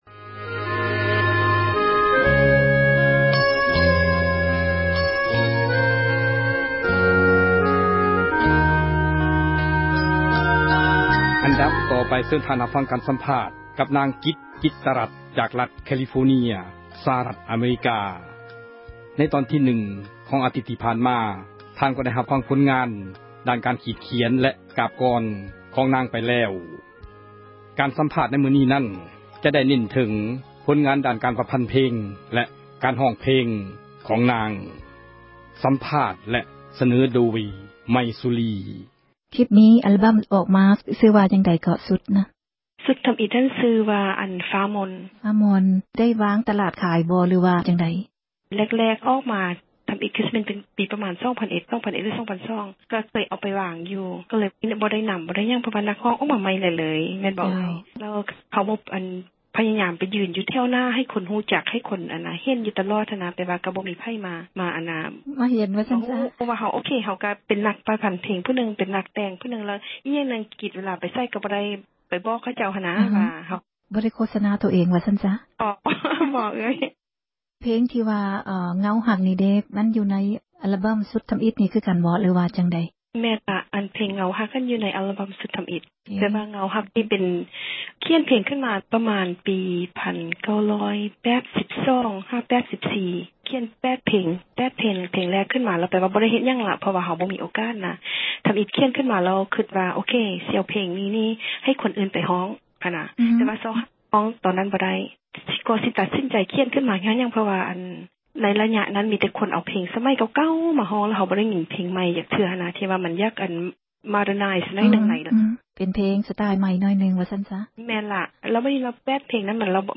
ສັມພາດສິລປິນຍິງ